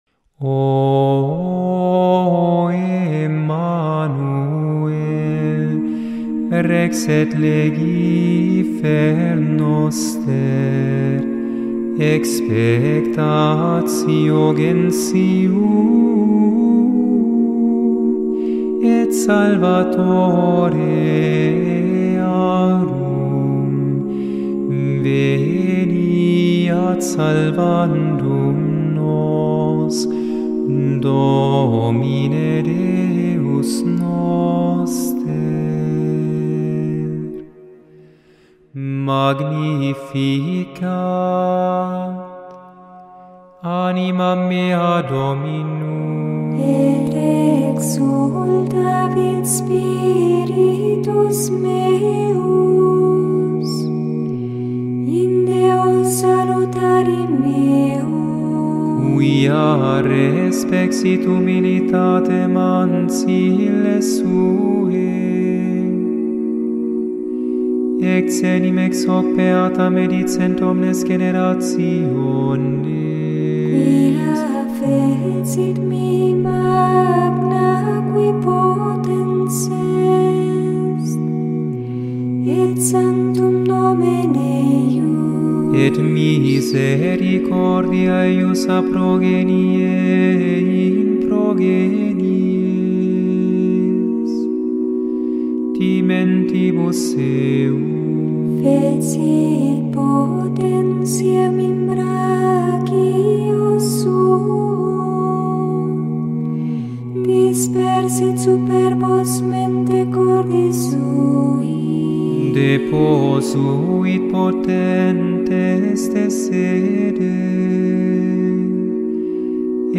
• Chaque jour, du 17 au 23 décembre (soir), on chante une antienne « Ô » dédiée à un Nom divin du Christ, tirée des prophéties de l’Ancien Testament.
Et voici des manifique enregistrement de Harpa Dei sur YouTube :
23dec-O-EMMANUEL-Antifonas-O-y-Magnificat.mp3